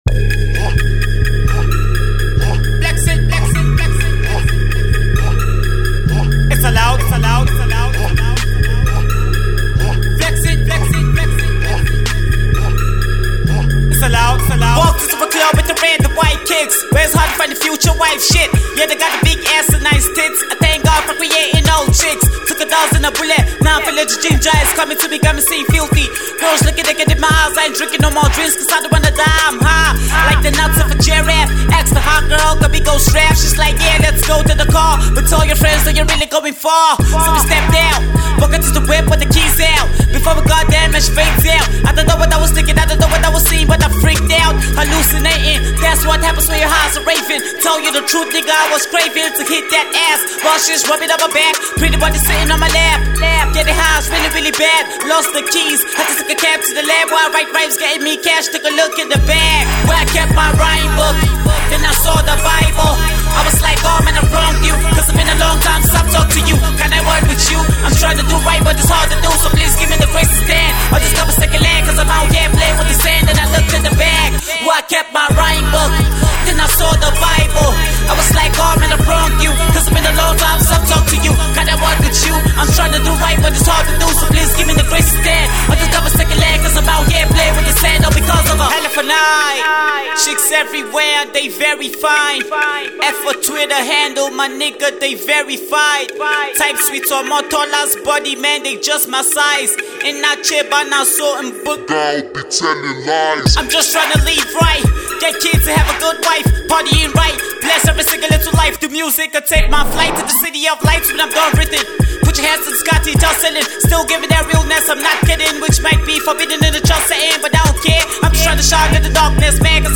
Hip-Hop
it’s cast this time on a talented and budding rapper